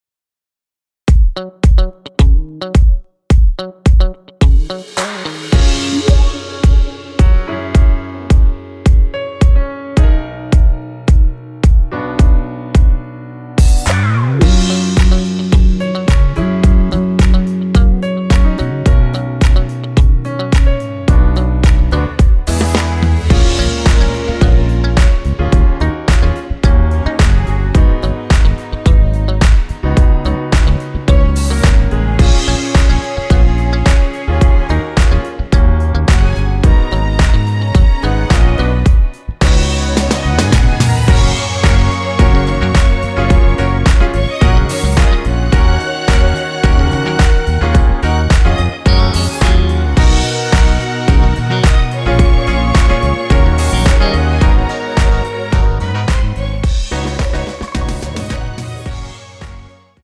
원키에서(+2)올린 MR입니다.
F#
앞부분30초, 뒷부분30초씩 편집해서 올려 드리고 있습니다.
중간에 음이 끈어지고 다시 나오는 이유는